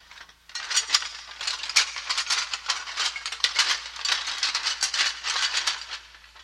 Звук руху броні